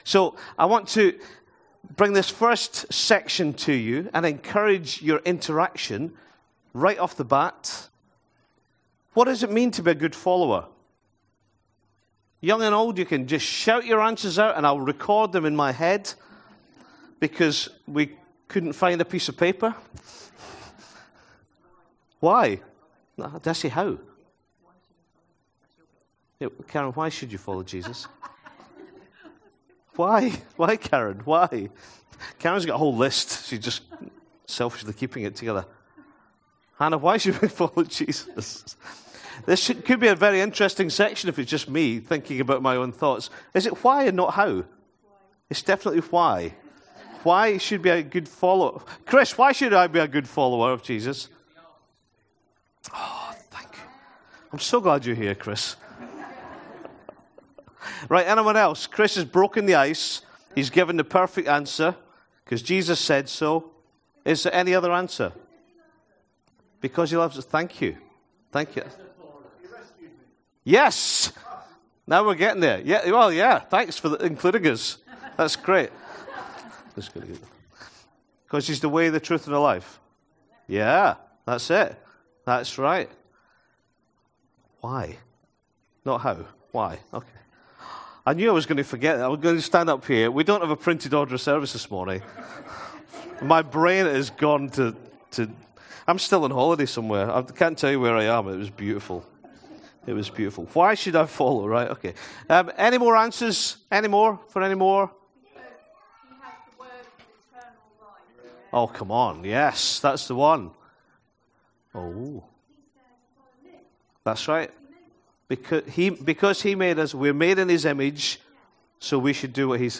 25-Aug-sermon.mp3